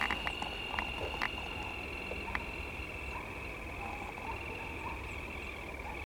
Rufous-sided Crake (Laterallus melanophaius)
Life Stage: Adult
Location or protected area: Parque Natural Municipal Ribera Norte (San Isidro)
Condition: Wild
Certainty: Recorded vocal